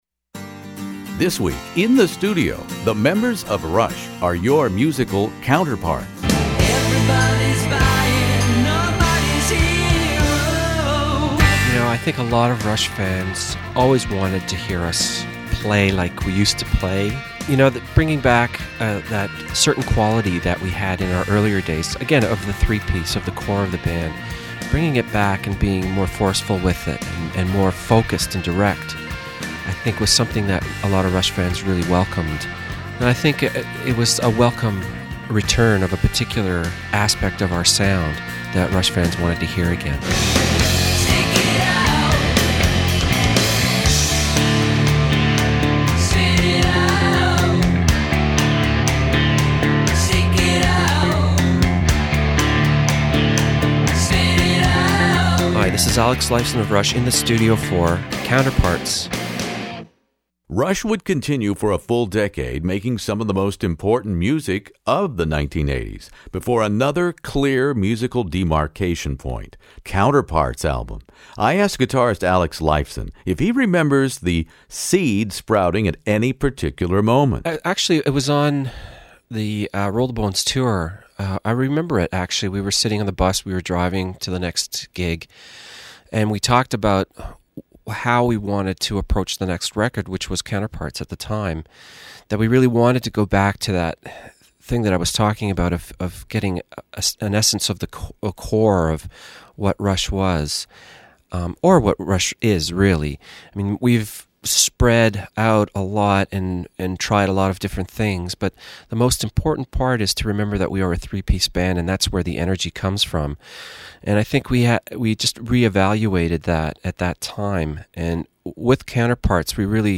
One of the world's largest classic rock interview archives, from ACDC to ZZ Top, by award-winning radio personality Redbeard.
Alex Lifeson is my guest here In the Studio for Counterparts.